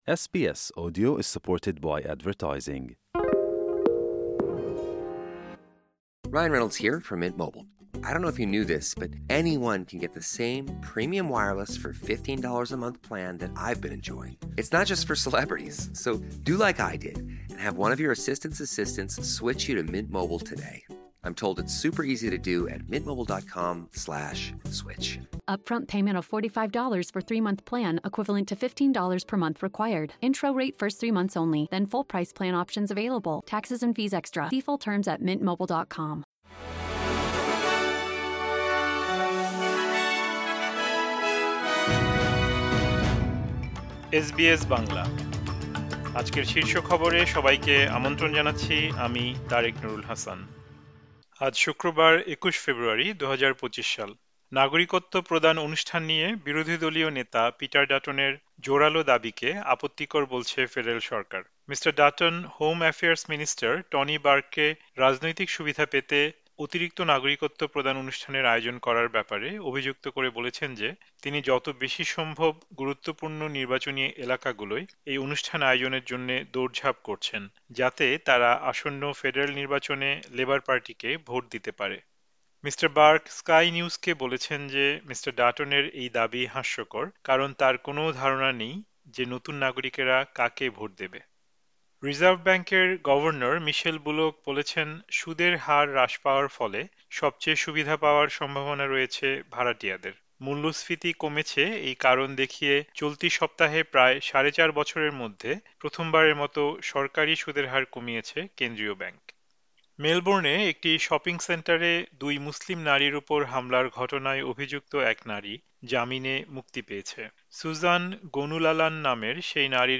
অস্ট্রেলিয়ার জাতীয় ও আন্তর্জাতিক সংবাদের জন্য আজকের এসবিএস বাংলা শীর্ষ খবর শুনতে উপরের অডিও-প্লেয়ারটিতে ক্লিক করুন।